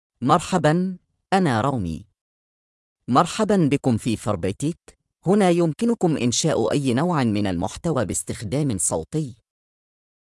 Rami — Male Arabic (Lebanon) AI Voice | TTS, Voice Cloning & Video | Verbatik AI
Rami is a male AI voice for Arabic (Lebanon).
Voice sample
Listen to Rami's male Arabic voice.
Rami delivers clear pronunciation with authentic Lebanon Arabic intonation, making your content sound professionally produced.